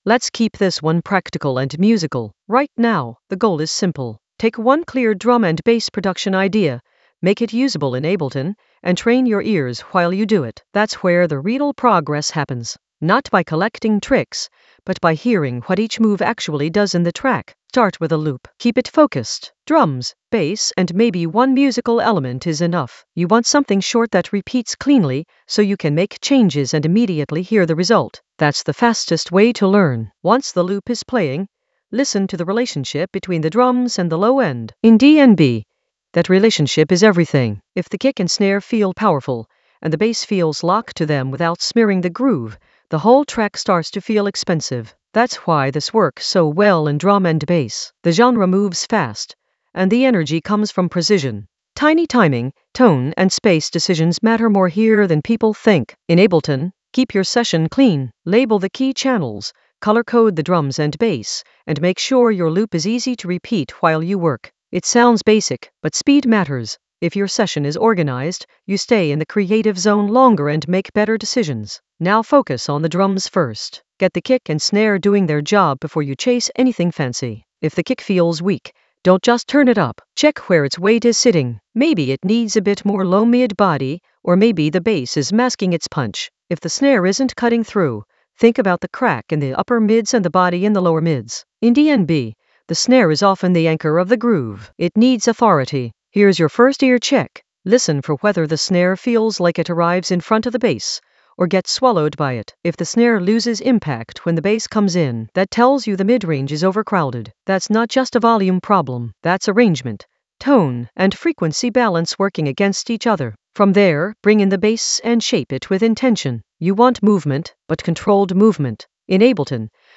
An AI-generated advanced Ableton lesson focused on DJ SS blueprint: design a darkstep reese in Ableton Live 12 for fierce drum and bass weight in the Basslines area of drum and bass production.
Narrated lesson audio
The voice track includes the tutorial plus extra teacher commentary.